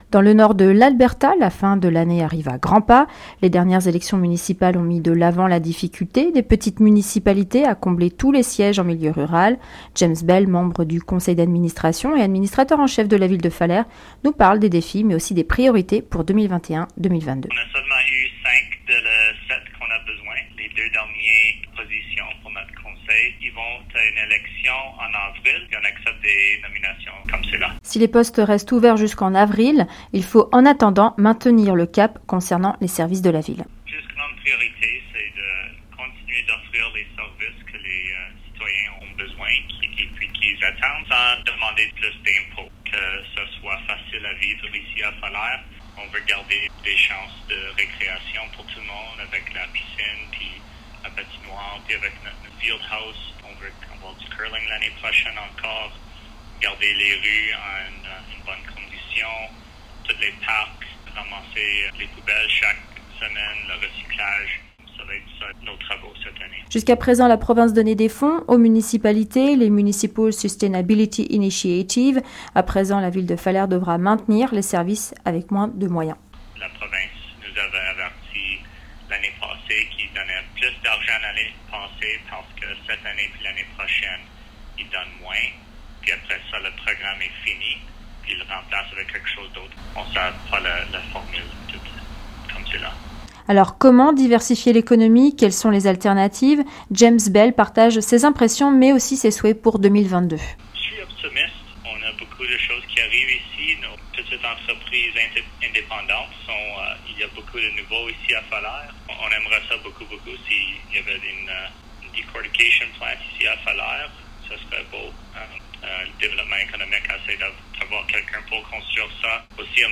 James Bell, membre du conseil municipal de Falher nous parle de l'impératif de garder un certain équilibre au niveau des services et se dit optimiste pour 2022.